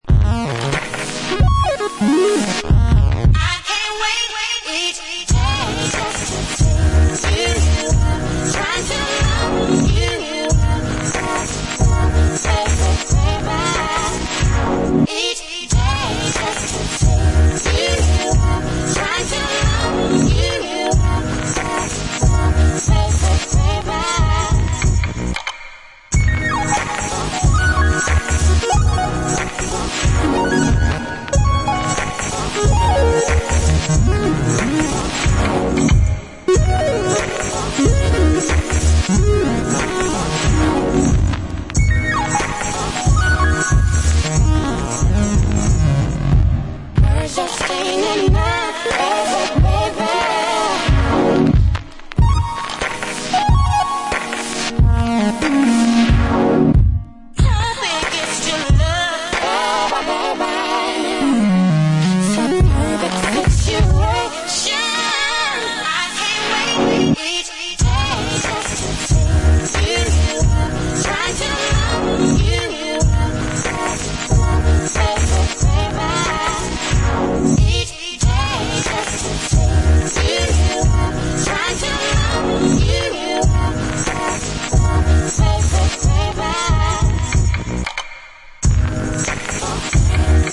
supplier of essential dance music
Soul Bass